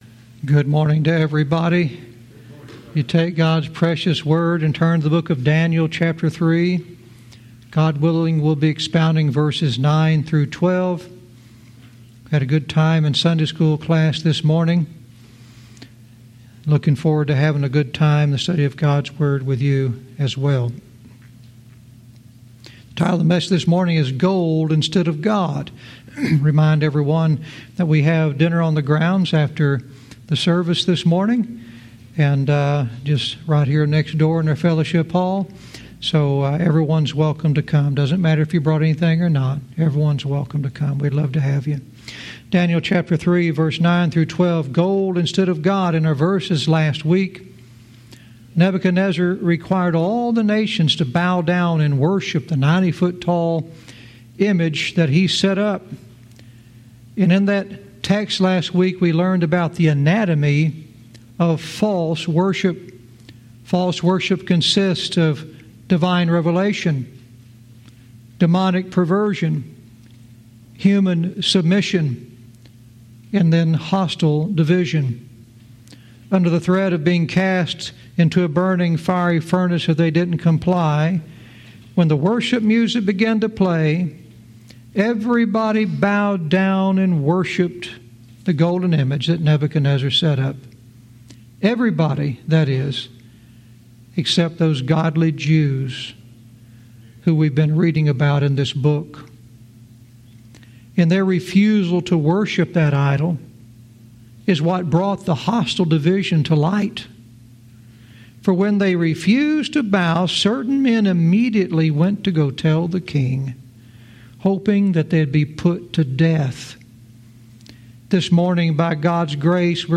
Verse by verse teaching - Daniel 3:9-12 "Gold instead of God"